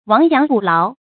注音：ㄨㄤˊ ㄧㄤˊ ㄅㄨˇ ㄌㄠˊ
讀音讀法：
亡羊補牢的讀法